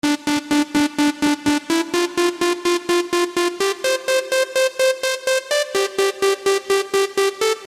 嗡嗡声领队1
描述：嗡嗡作响的合成器主音 126 BPM
Tag: 126 bpm Dance Loops Synth Loops 1.28 MB wav Key : Unknown